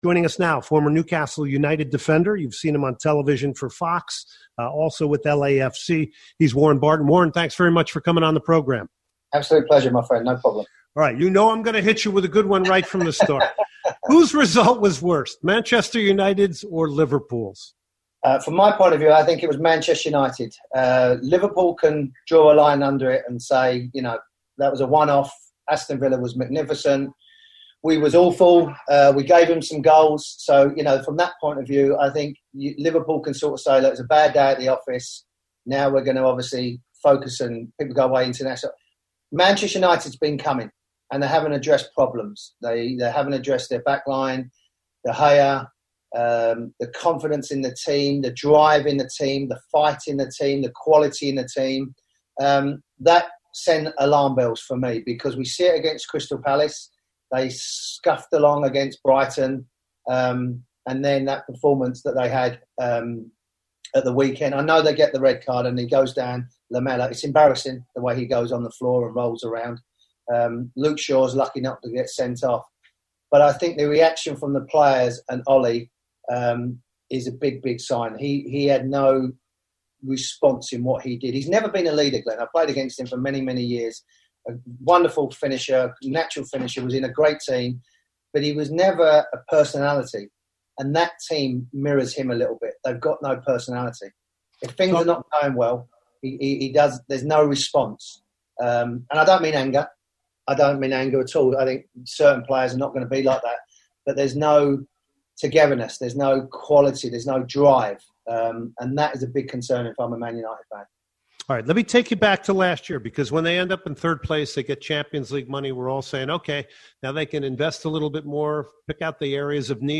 10.08.2020 Interview with Warren Barton Former Newcastle player